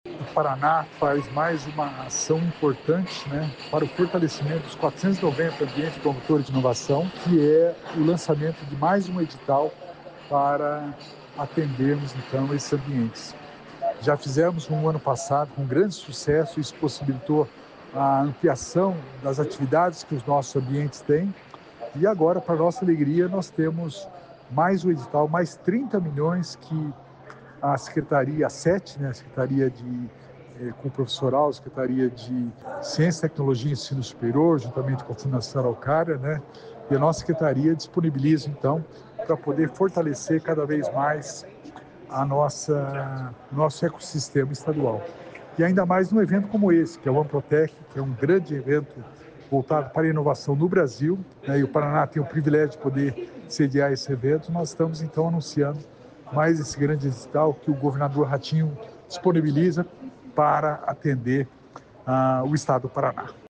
Sonora do secretário estadual da Inovação e Inteligência Artificial do Paraná, Alex Canziani, sobre novo edital para fortalecer o sistema estadual de inovação